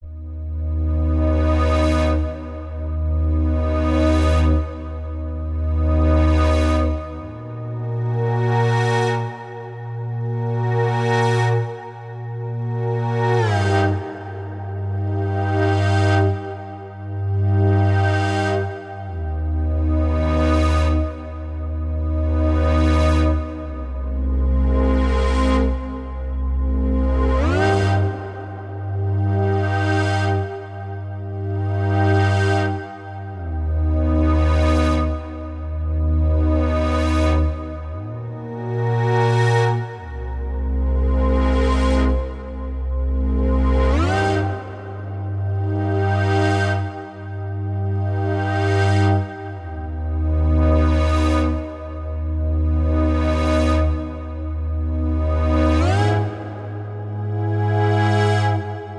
Onde_synth.wav